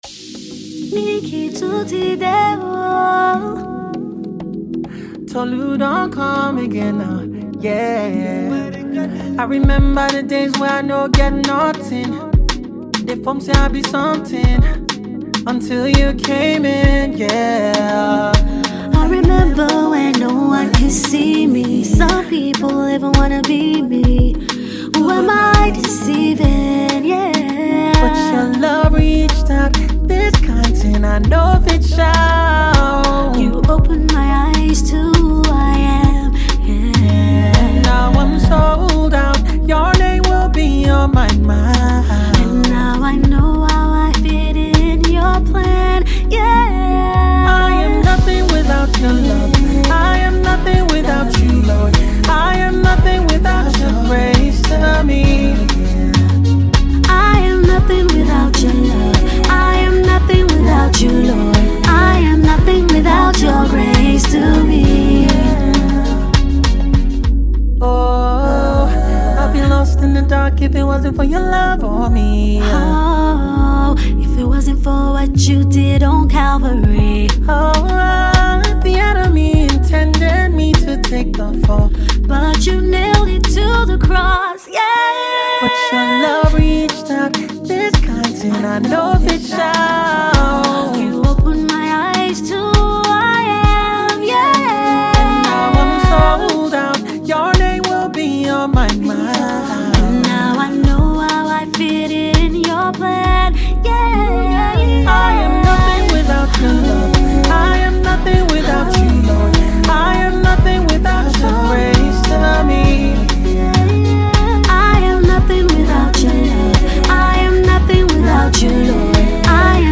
Multiple Award winning Soul Singer